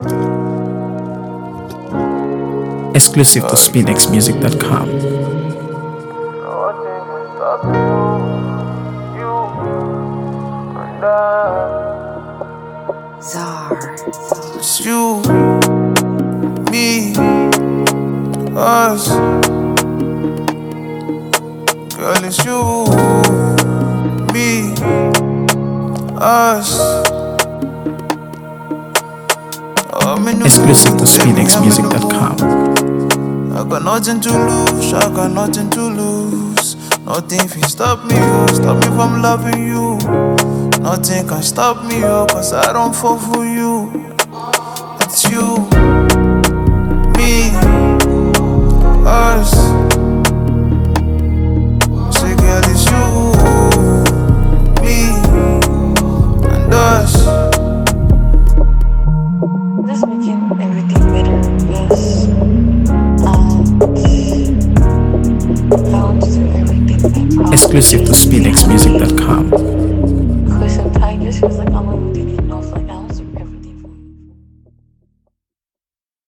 AfroBeats | AfroBeats songs
With smooth, soulful vocals and a compelling sonic backdrop
With crisp instrumentation and a clean, dynamic mix